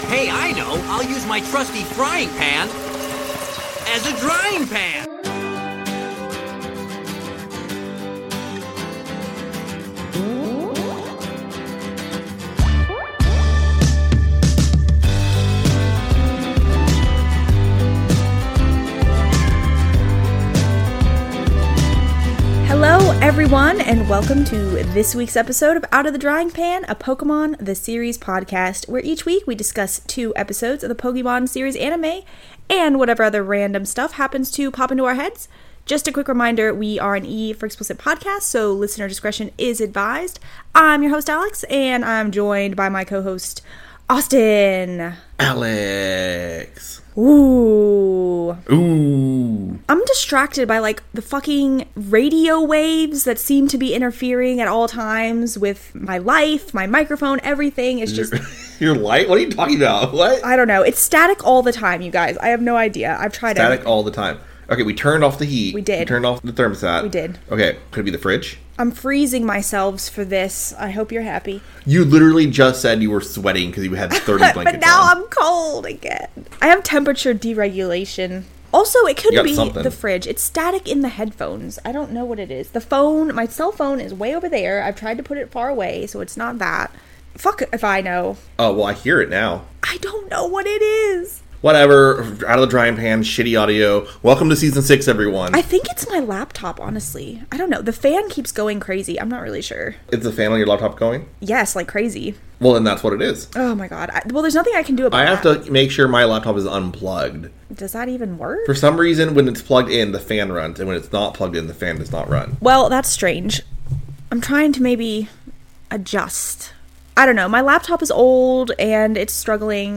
A podcast where three filthy casual Millennials look back at the English dub of Pokémon the Series, and pick it apart relentlessly.